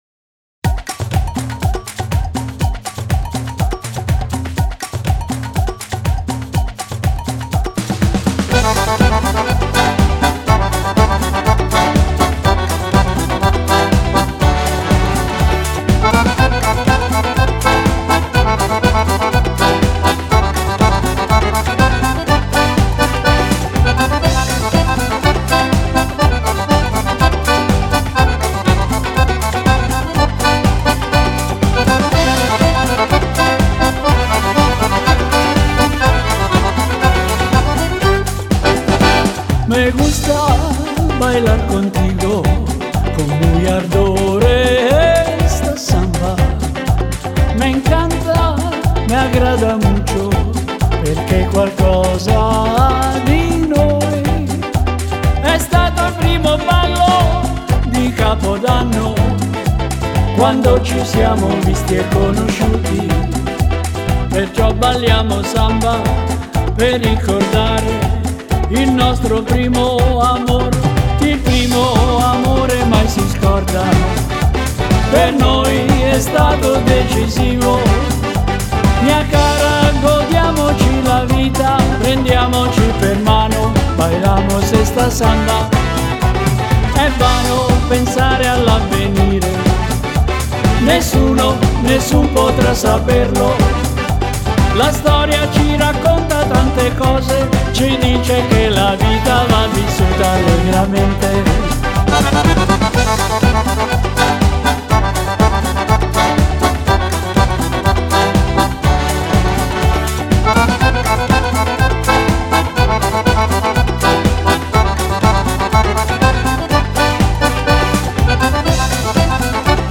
Samba
Dieci canzoni ballabili
Fisarmonica